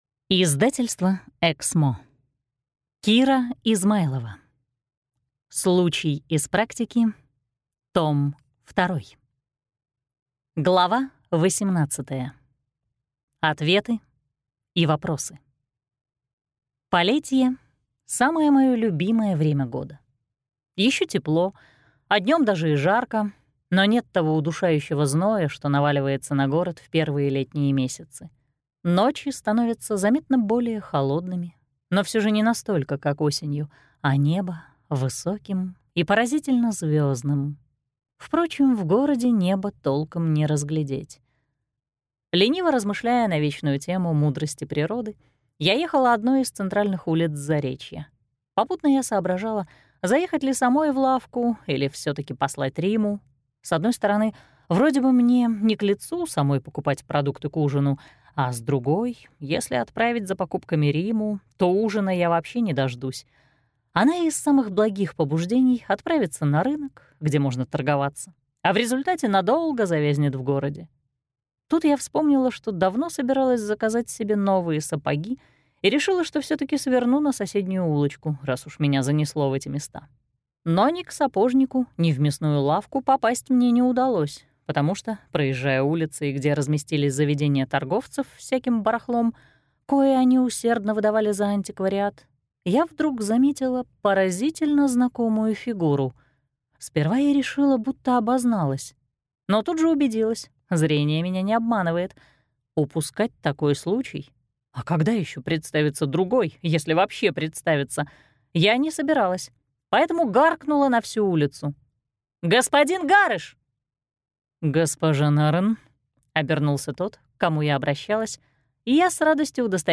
Аудиокнига «Високосный февраль».